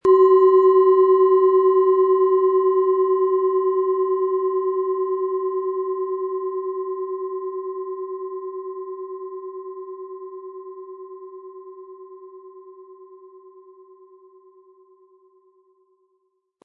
Planetenschale® Beschützt fühlen & Geistige Wachheit mit Biorhythmus Geist, Ø 9,9 cm, 100-180 Gramm inkl. Klöppel
Planetenton 1
Die Planetenklangschale Biorhythmus Geist ist von Hand gefertigt worden.
Im Audio-Player - Jetzt reinhören hören Sie genau den Original-Ton der angebotenen Schale.
Lieferung mit richtigem Schlägel, er lässt die Planetenschale Biorhythmus Geist harmonisch und wohltuend schwingen.